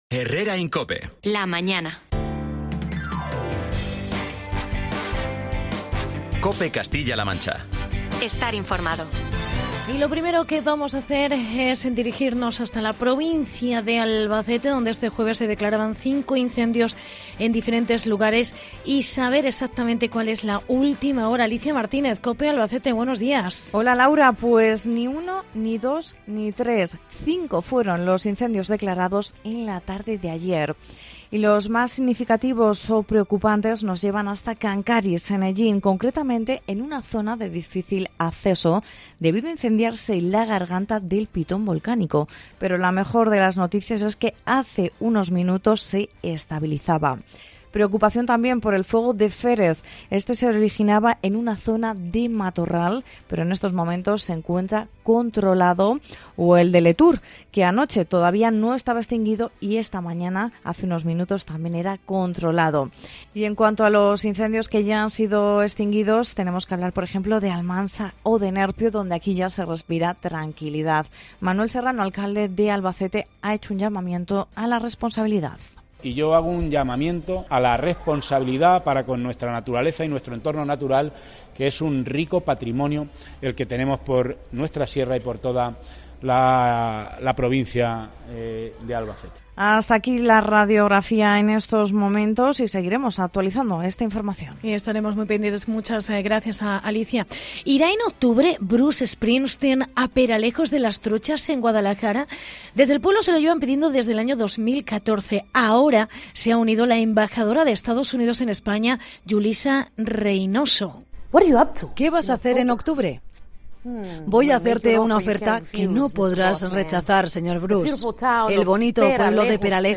INFORMATIVO